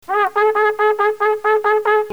The Teruah is a series of nine short blasts.
teruah.mp3